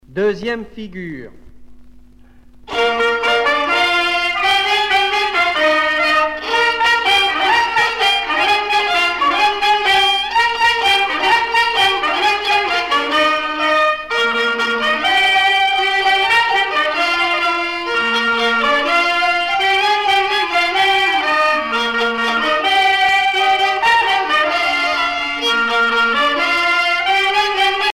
danse : quadrille ; danse : ronde : boulangère ;
Pièce musicale éditée